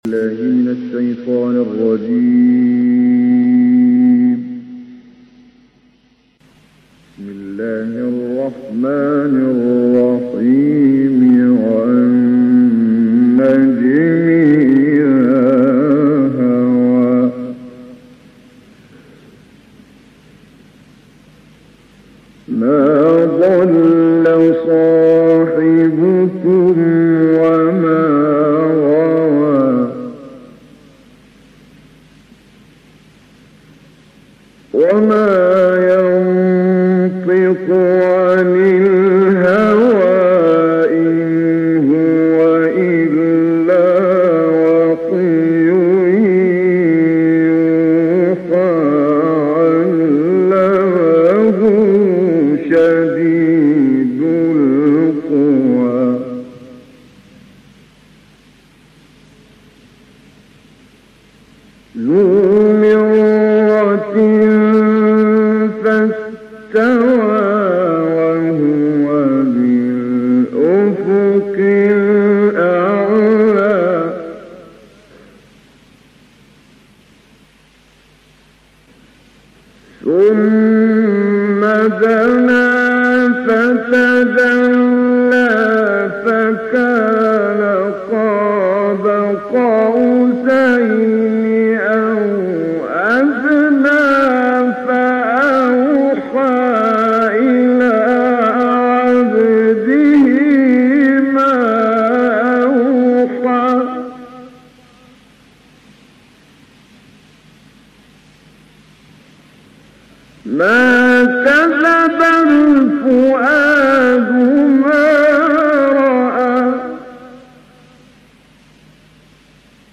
استعاذه، بسمله و آیه اول را با بیات شروع می‌کند.
اینجا هم در کلمه هیَ روی فتحه «یَ» ناکوکی اتفاق می‌افتد در صدای مرحوم «کامل یوسف» که کم و بیش از این اتفاق‌ها در تلاوت قاریان بزرگ هم می‌افتد.